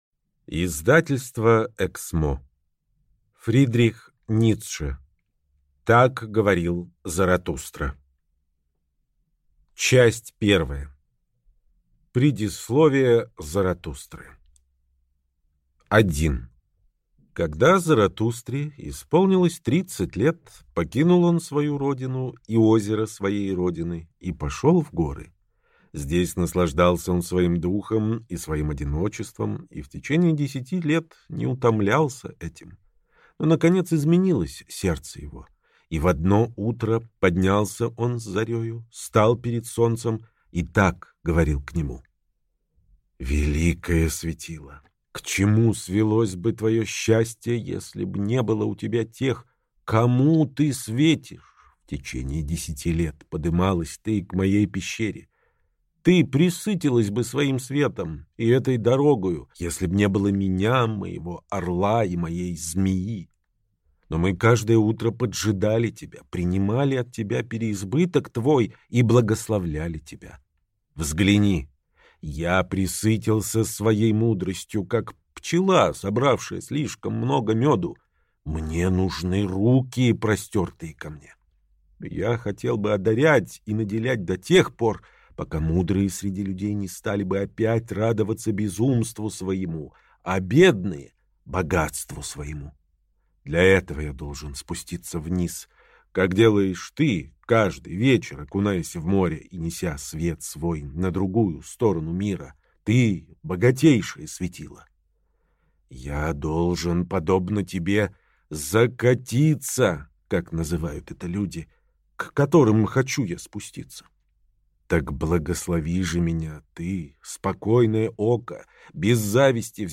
Аудиокнига Так говорил Заратустра | Библиотека аудиокниг